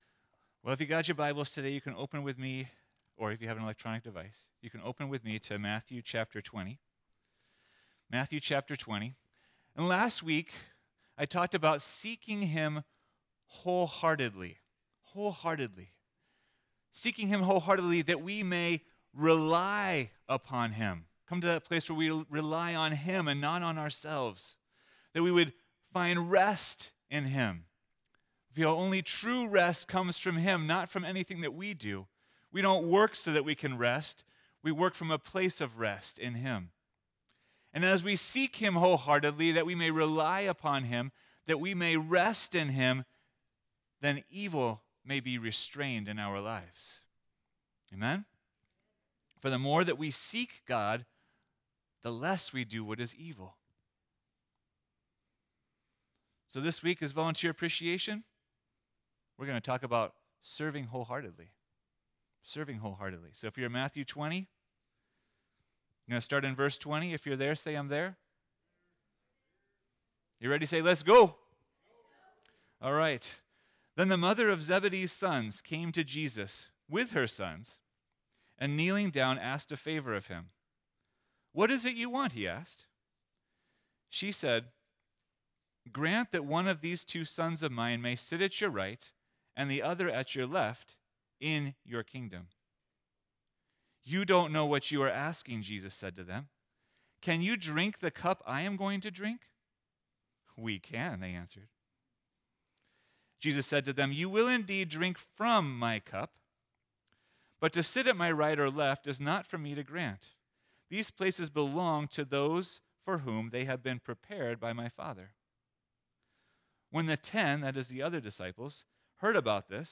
2025 Serving Wholeheartedly Preacher